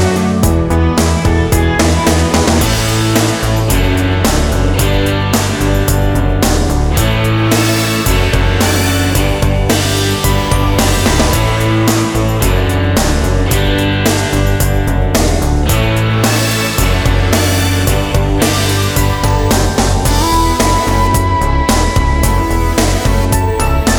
no Backing Vocals Easy Listening 3:44 Buy £1.50